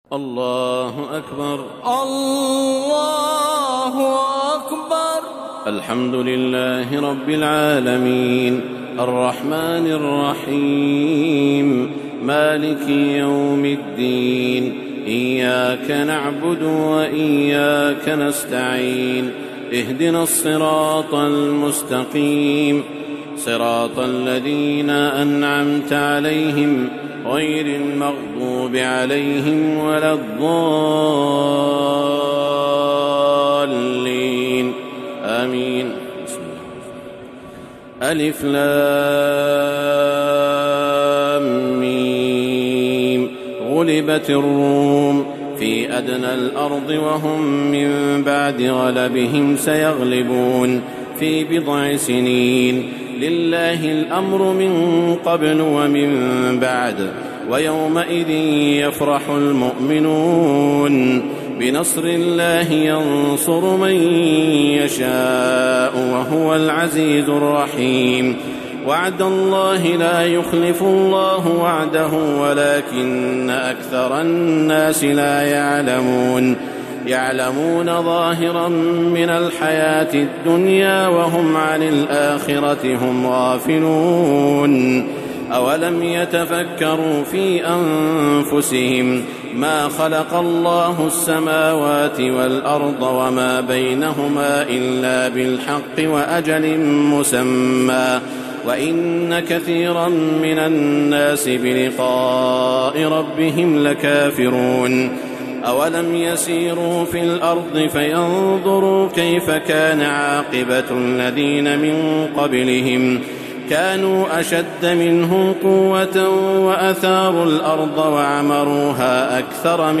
Listen online and download beautiful Quran Recitation of Surah Al Fatiha and Surah Ar Room. This tilawat was recorded in Haramain Mecca. Listen this tilawat in the beautiful voice of Imam e Kabaa Sheikh Saud Al-Shuraim.